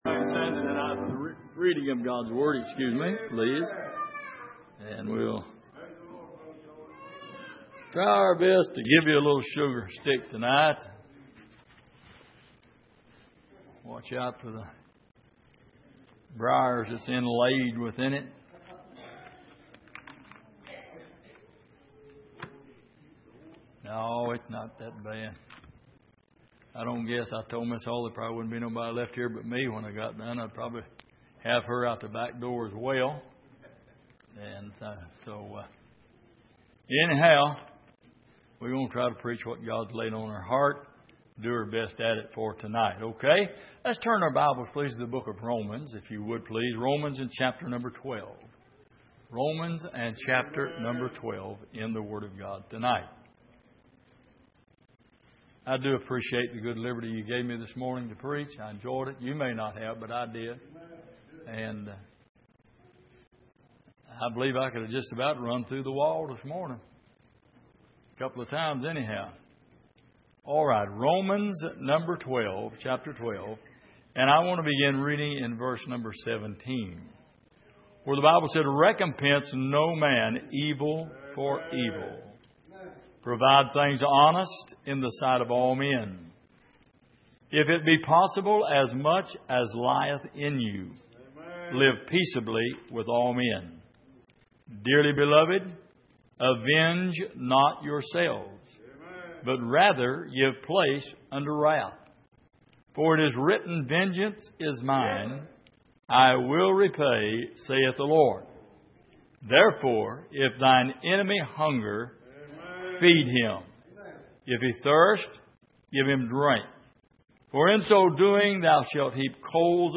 Miscellaneous Passage: Romans 12:17-21 Service: Sunday Evening Some Things We Need To Overcome « Has Your Water Run Out?